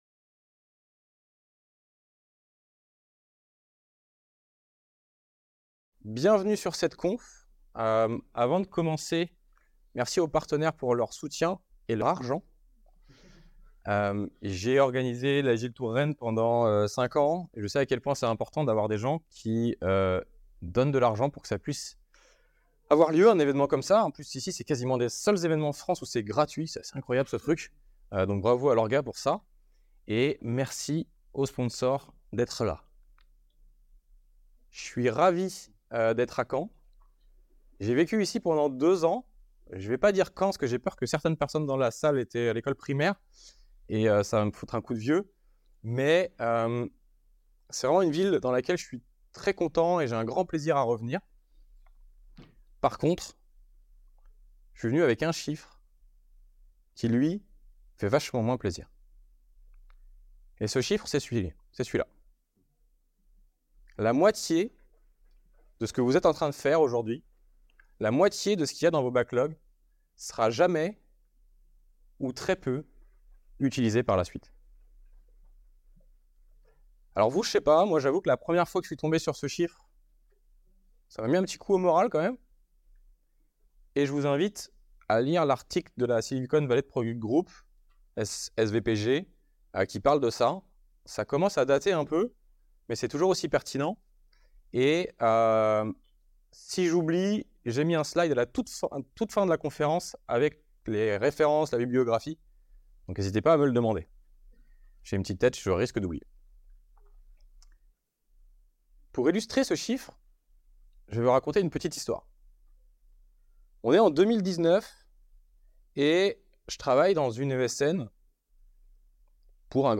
Le printemps agile 2024 fait son festival